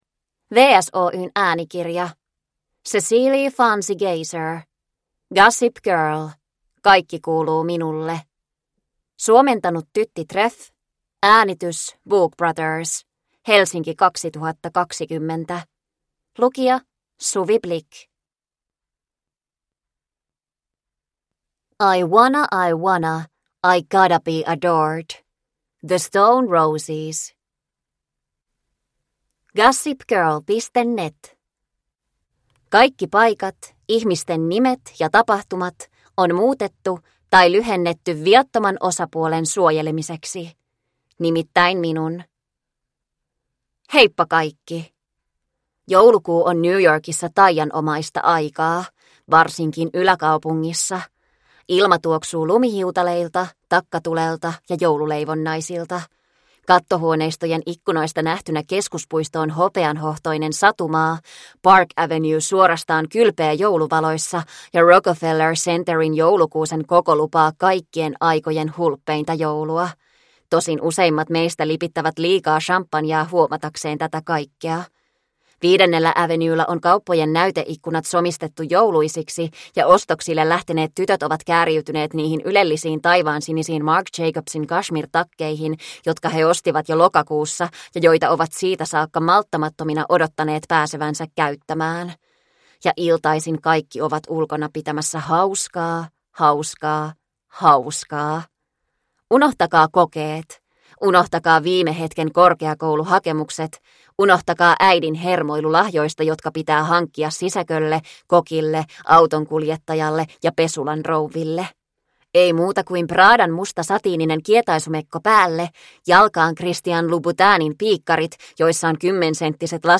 Kaikki kuuluu minulle – Ljudbok – Laddas ner